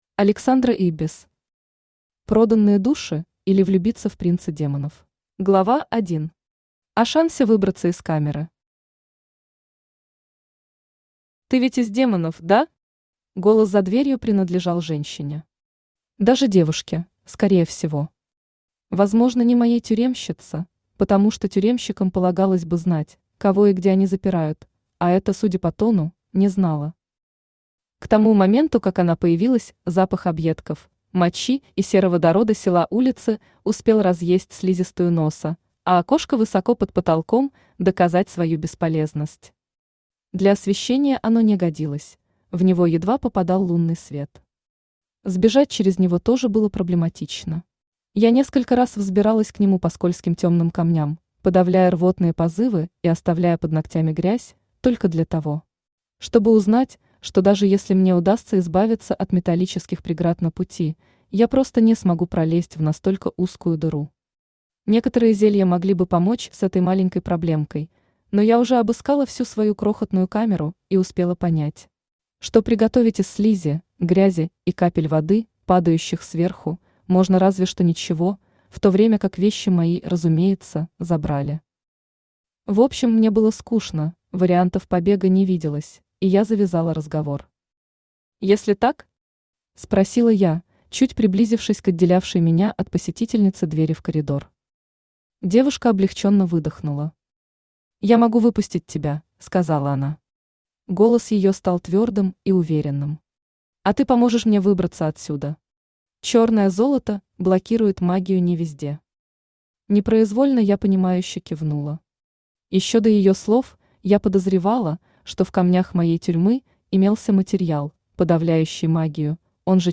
Aудиокнига Проданные души, или Влюбиться в принца демонов Автор Александра Ибис Читает аудиокнигу Авточтец ЛитРес.